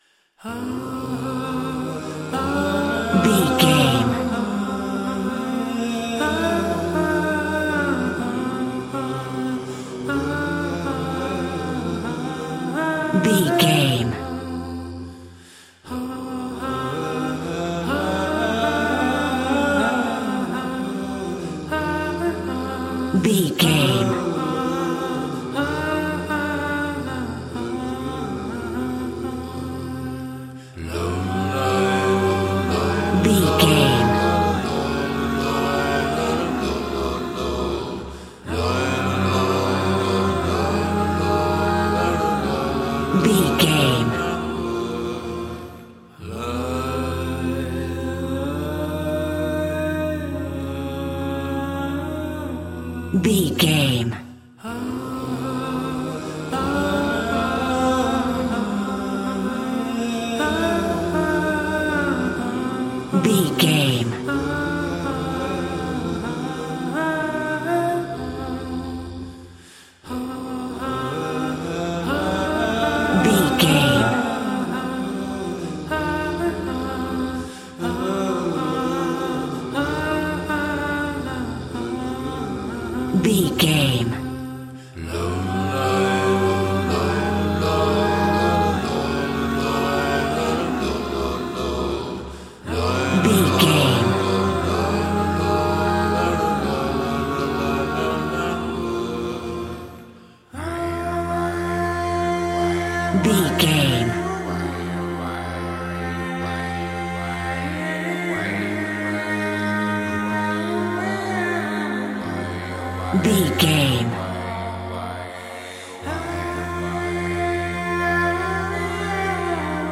Aeolian/Minor
groovy
inspirational